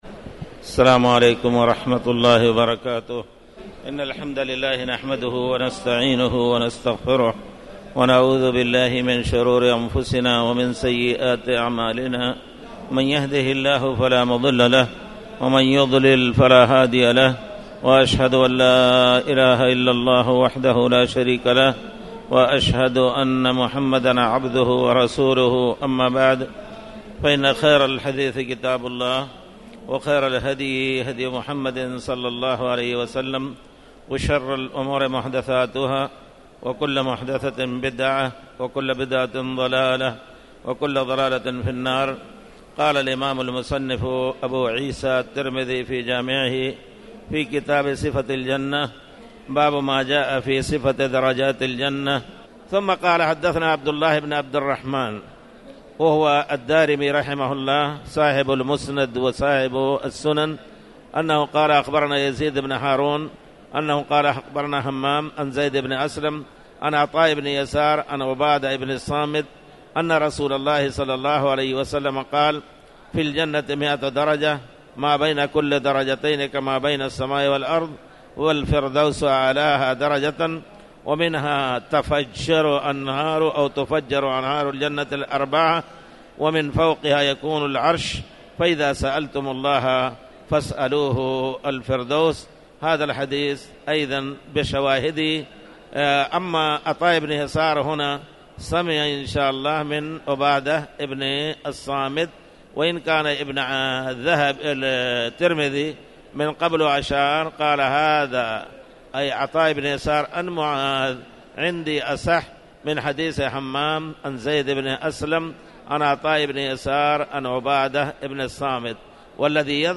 تاريخ النشر ٢٧ جمادى الآخرة ١٤٣٩ هـ المكان: المسجد الحرام الشيخ